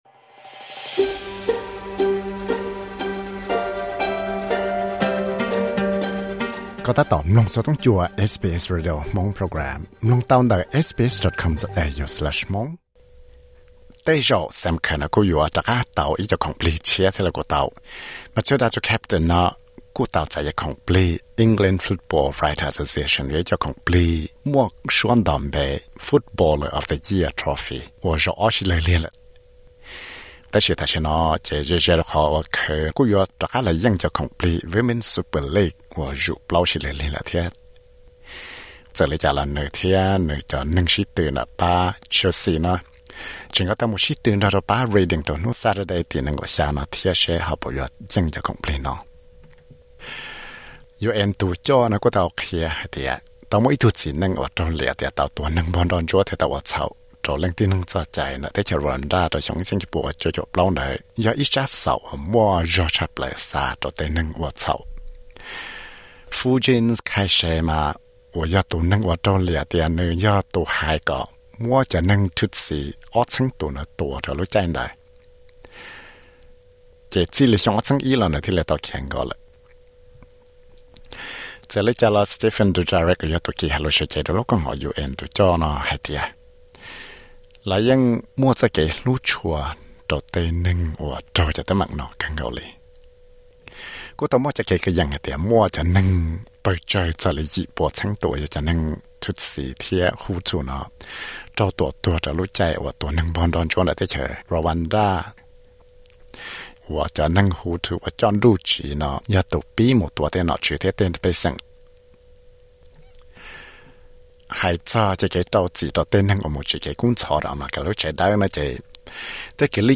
Xov xwm hnub zwj Kuab (Friday newsflash 26.05.2023).